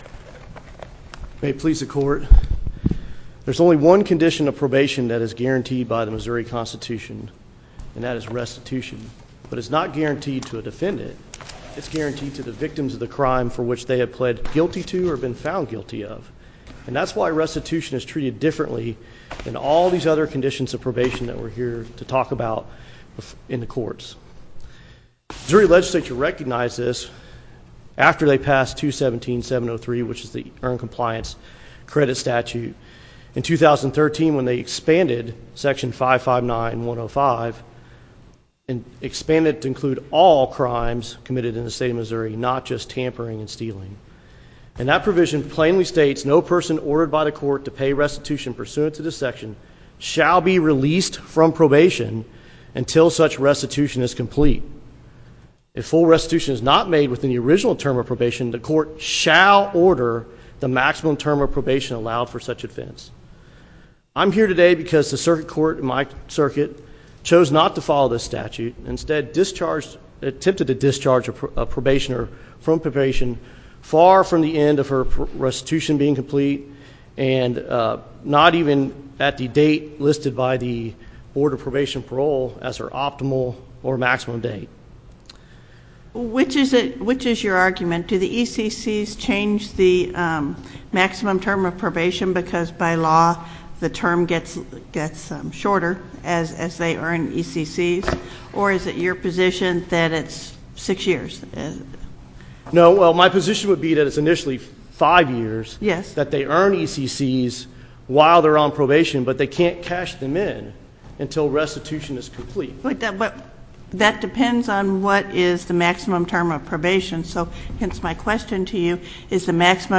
link to MP3 audio file of oral arguments in SC97198